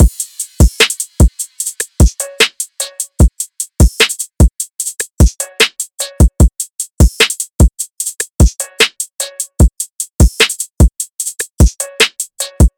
DRUM LOOPS
Problems (150 BPM – Gbm)